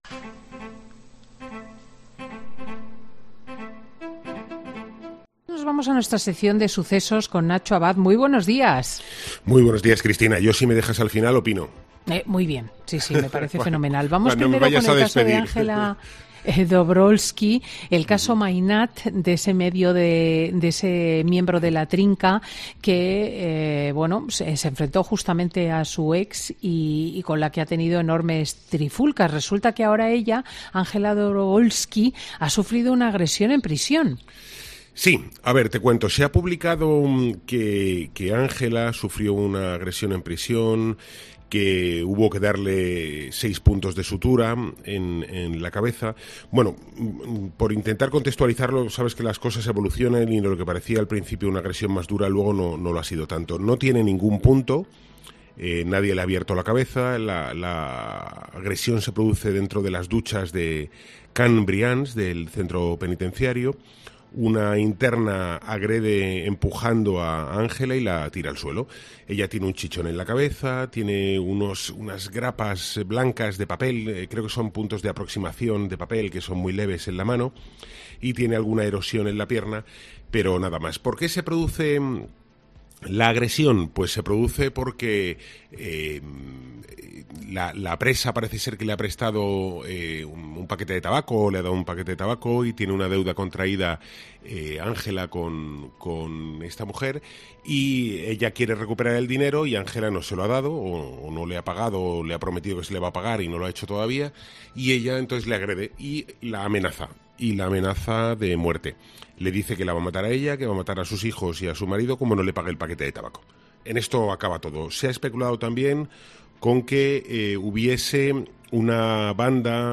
El periodista especializado en sucesos hace un repaso de todo el caso